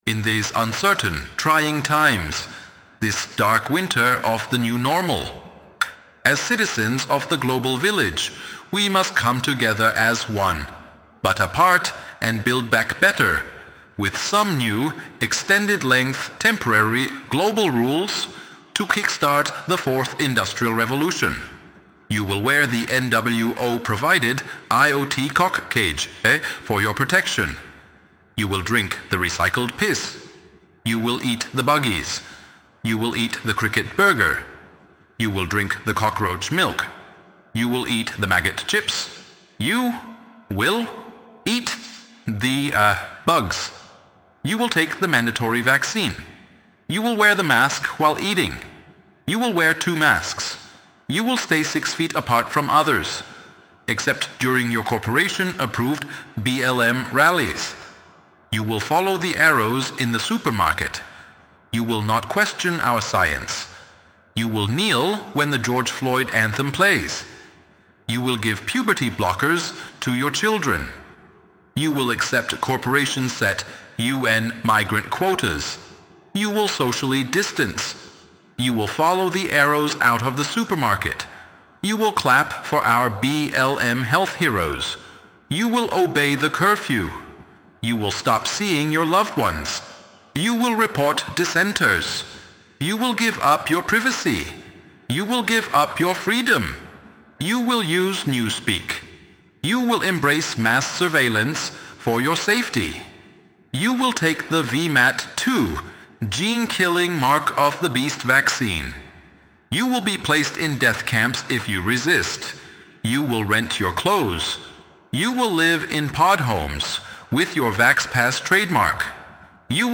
(2.48 MB, ElevenLabs_2025-03-21T21_3….mp3)
Do you sound like this?